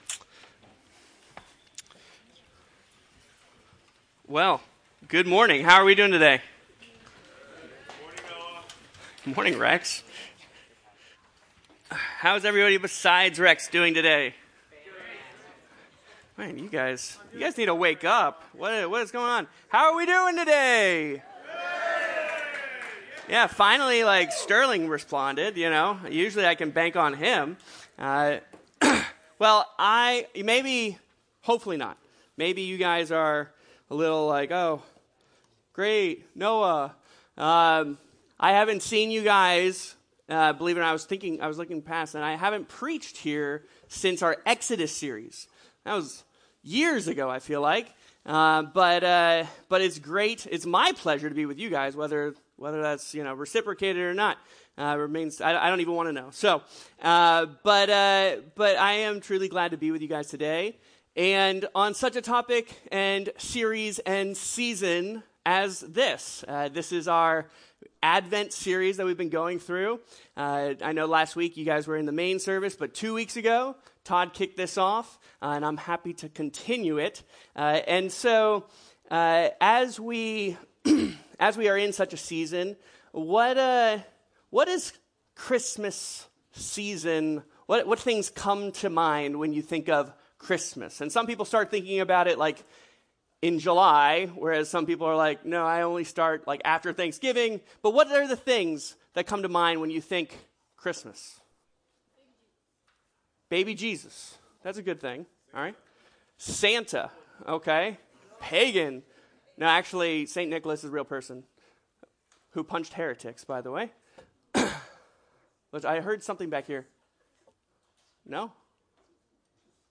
Teaching from the youth ministry of Reston Bible Church in Virginia.